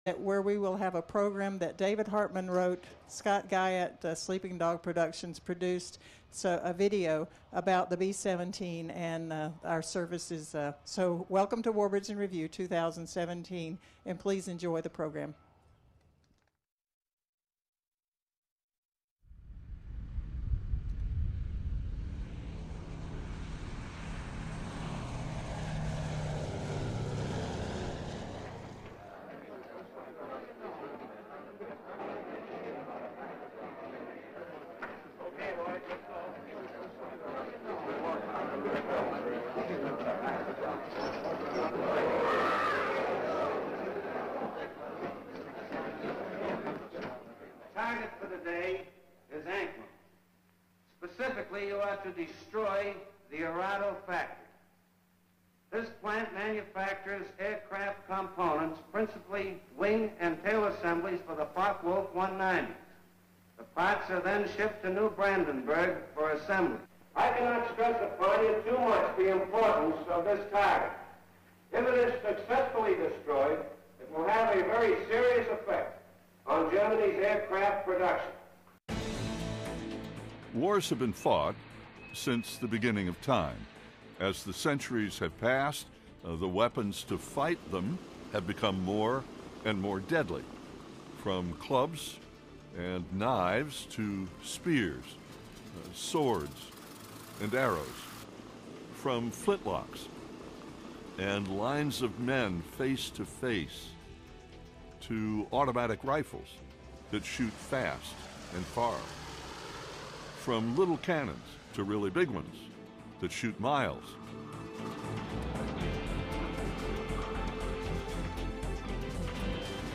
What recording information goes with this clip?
Warbirds In Review 8th Air Force/B-17 presentation next to the B-17 Madras Maiden took place in Warbird Alley at EAA AirVenture Oshkosh 2017